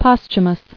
[post·hu·mous]